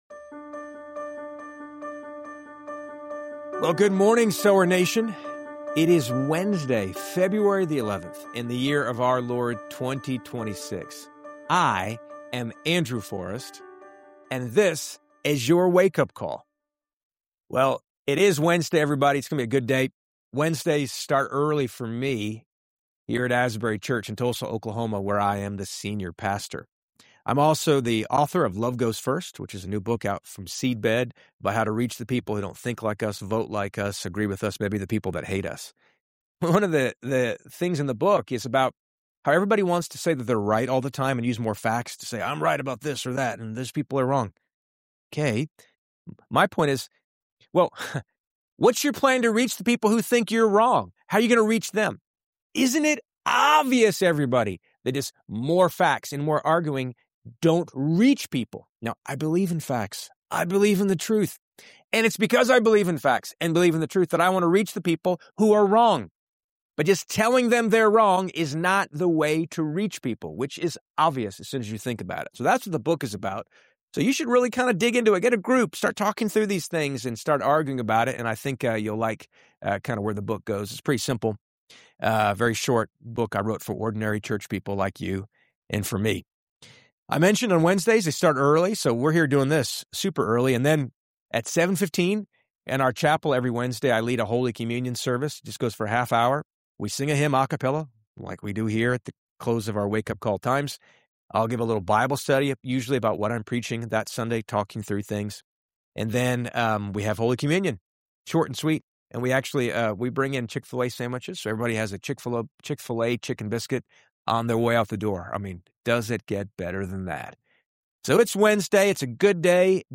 A powerful, fresh take on one of the greatest Methodist hymns, “And Can It Be,” including a heartfelt early-morning a cappella performance.